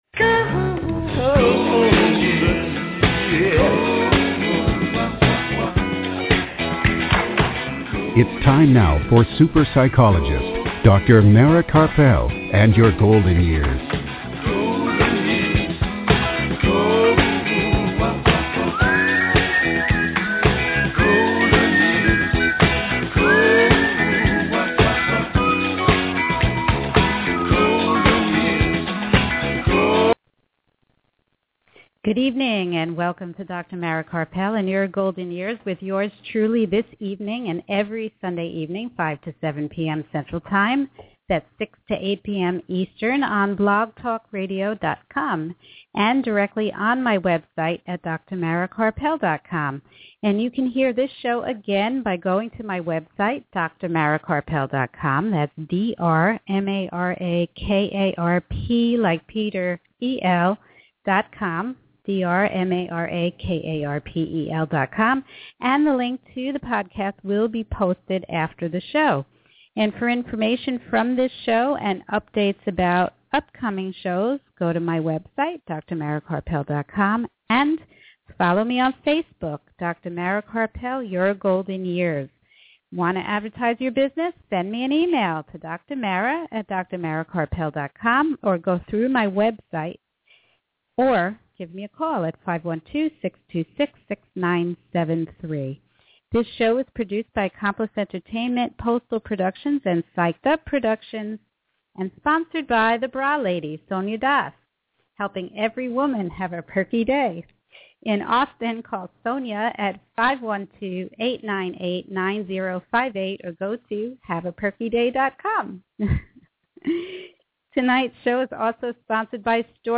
Missed the show? You can hear it all right here!